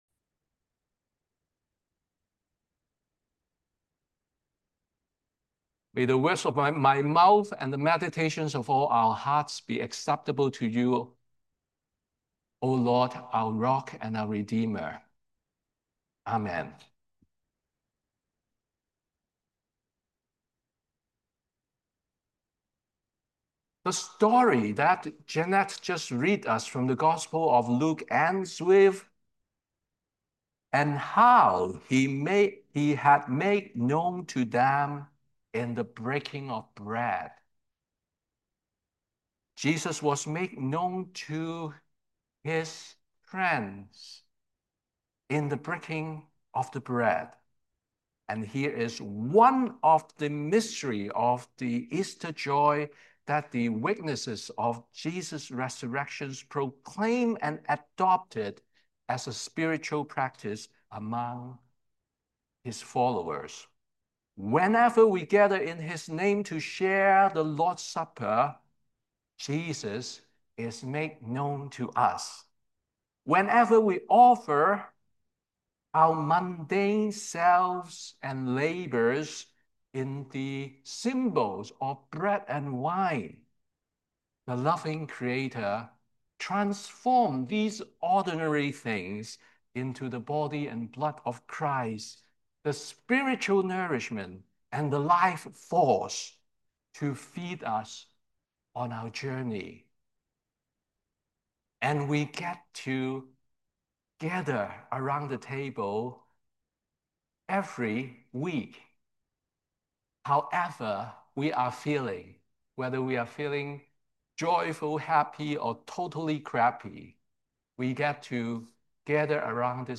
Sermon the Third Sunday of Easter